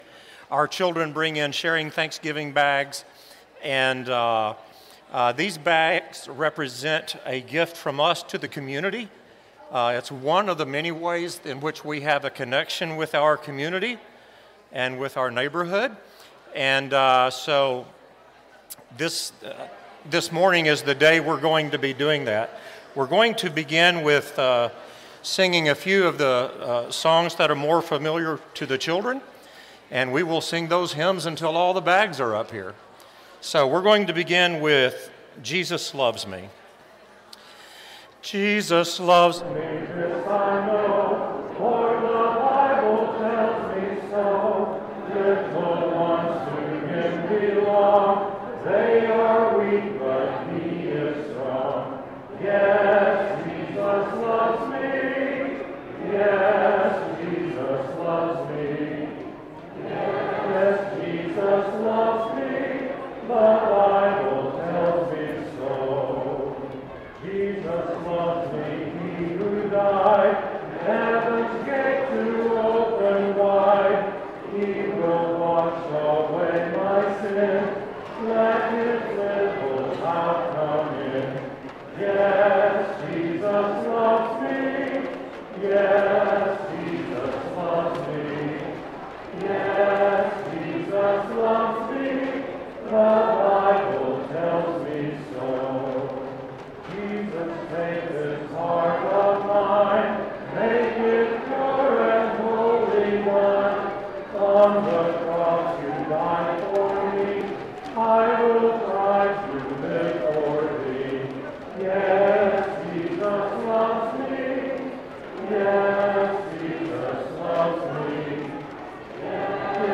Matthew 26:28, English Standard Version Series: Sunday AM Service